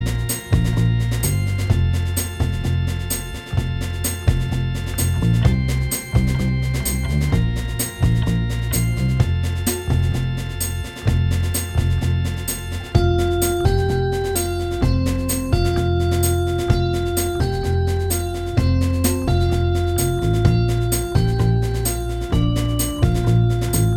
Minus Acoustic Guitar Pop (2010s) 3:24 Buy £1.50